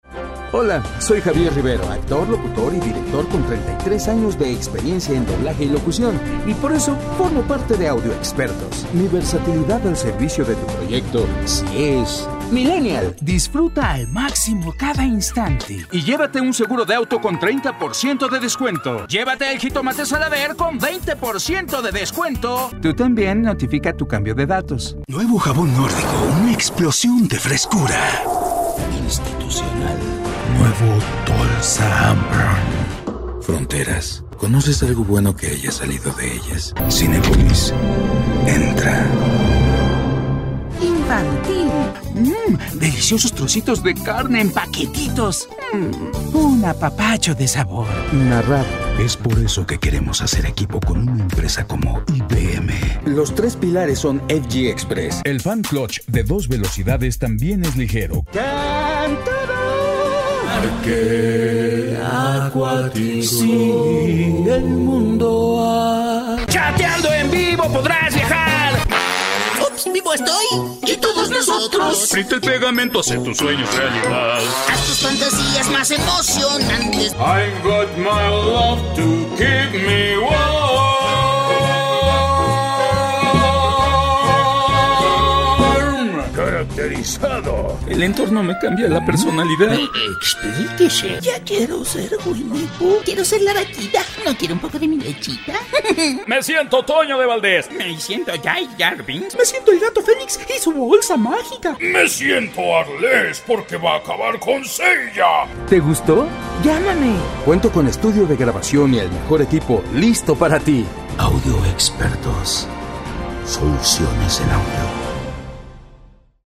Locutores
Rango de Voz: 18 a 35 años